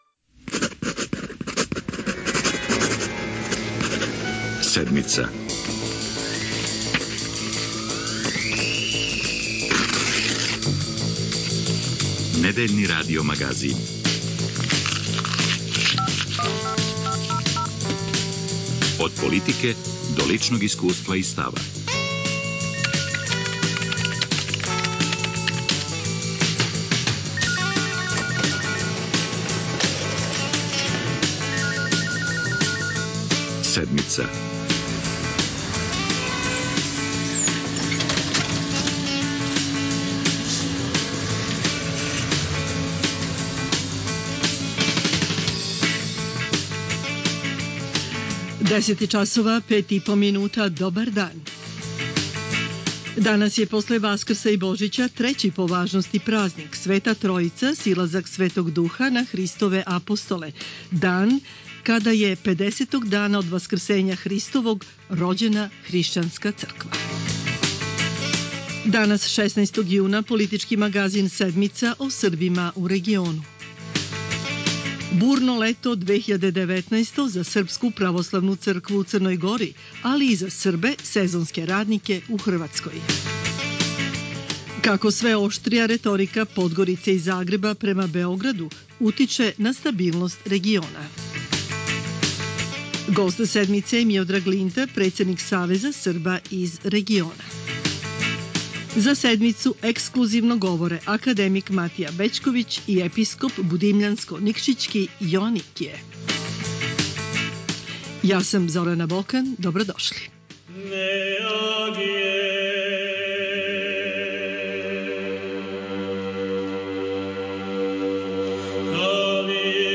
Гост "Седмице" је Миодраг Линта председник Савеза Срба из региона.